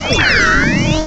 pokeemerald / sound / direct_sound_samples / cries / rotom.aif